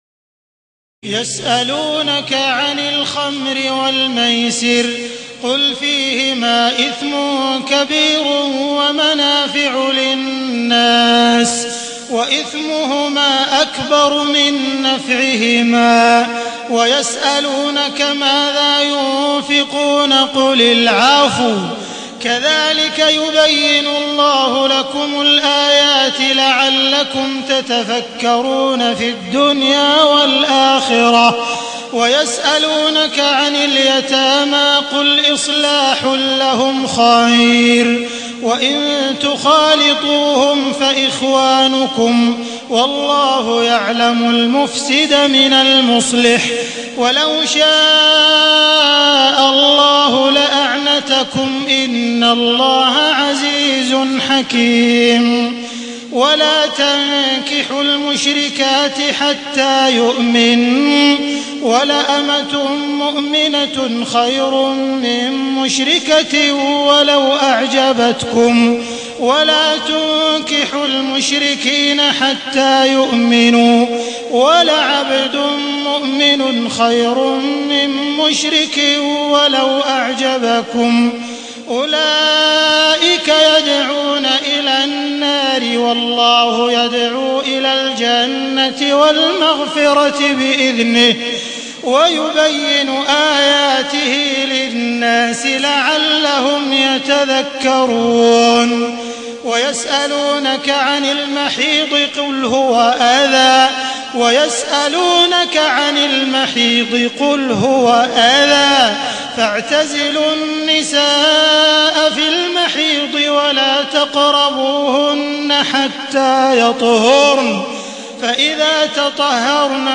تهجد ليلة 22 رمضان 1432هـ من سورة البقرة (219-252) Tahajjud 22 st night Ramadan 1432H from Surah Al-Baqara > تراويح الحرم المكي عام 1432 🕋 > التراويح - تلاوات الحرمين